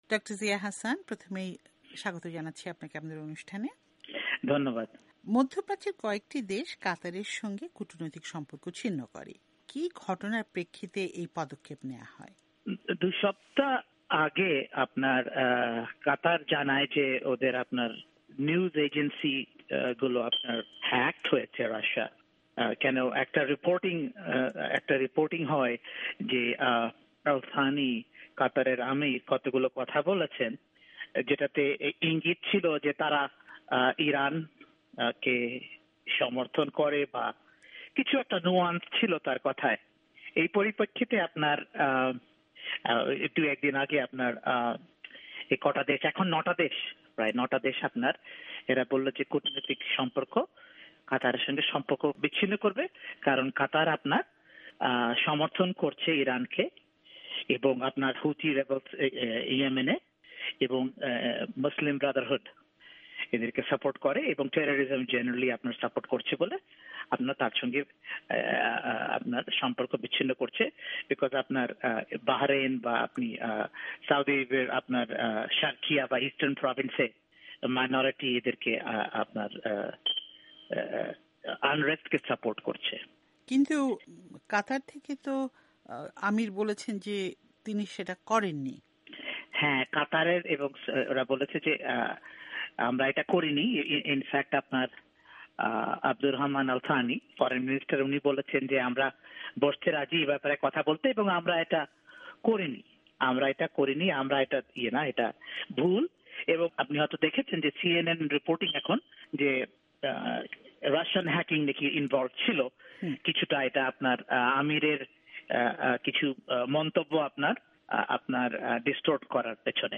সাক্ষাৎকার